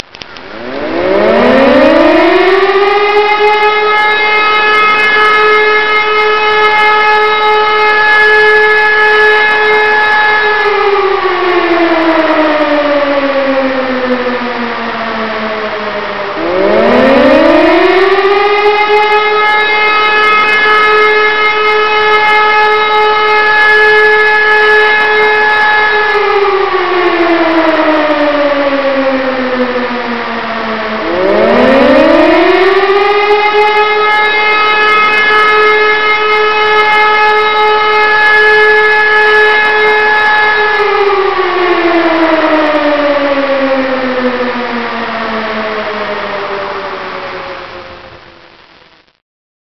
ملف:Motorsirene - Feuerwehralarm.ogg - المعرفة
Motorsirene_-_Feuerwehralarm.ogg.mp3